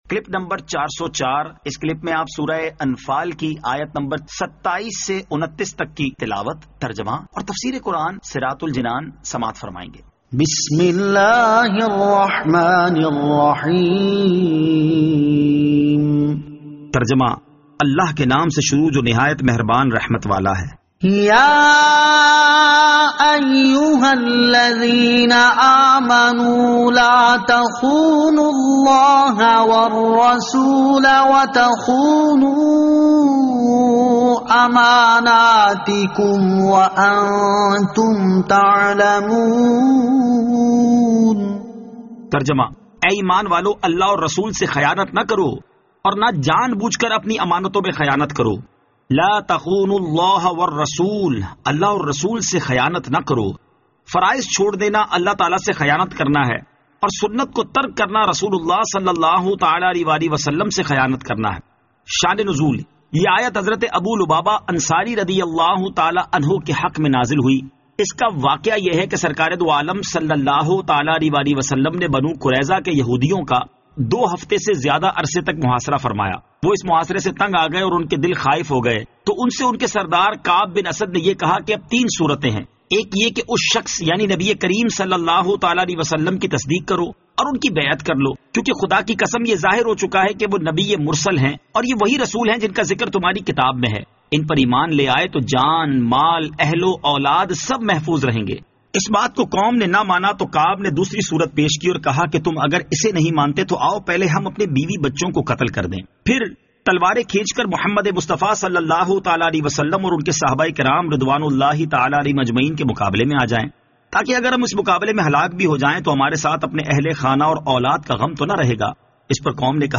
Surah Al-Anfal Ayat 27 To 29 Tilawat , Tarjama , Tafseer